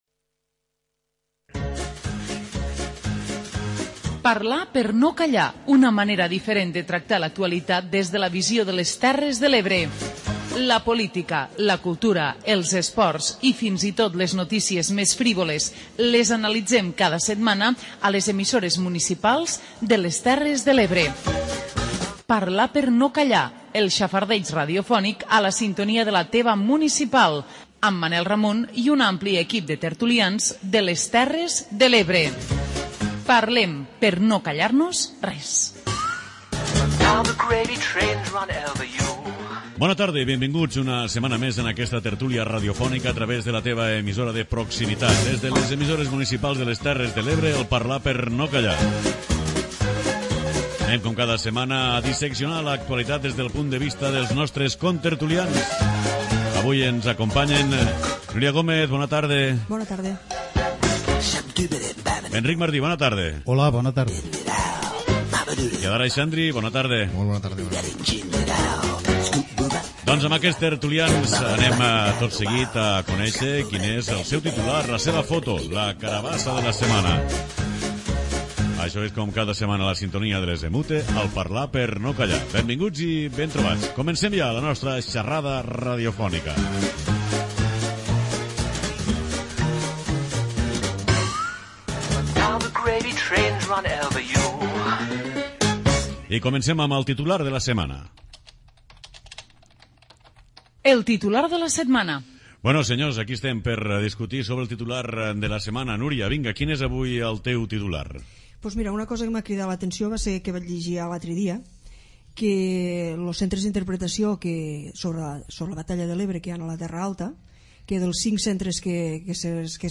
Parlar per no Callar, la tertúlia d'àmbit territorial d'EMUTE. 55 minuts de xerrada distesa amb gent que vol opinar. Busquem la foto, el titular i les carabasses de la setmana.